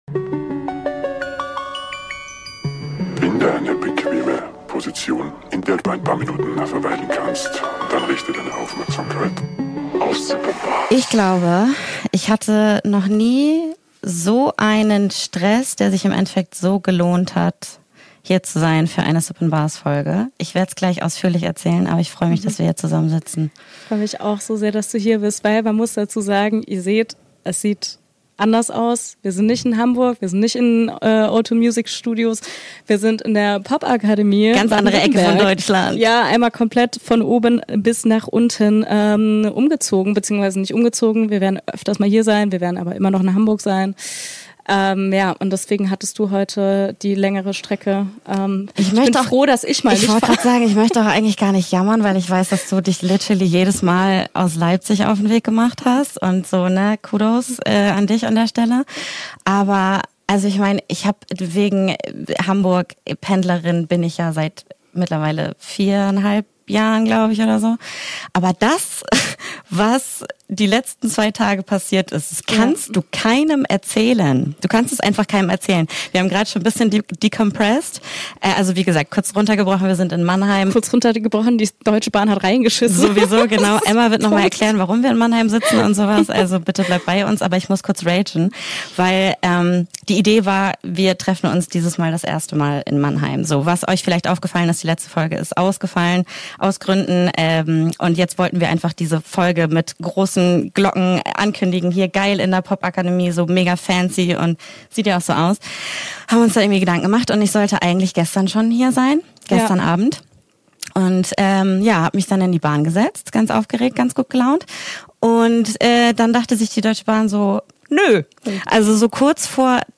Eine neue Folge wie ein Hindernislauf: trotz nicht oder zu spät kommenden Bahnen, Stromausfällen im Studio und einer Kamera, die manchmal tut, was sie will,